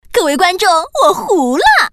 Index of /mahjong_paohuzi_Common_test/update/1658/res/sfx/putonghua/woman/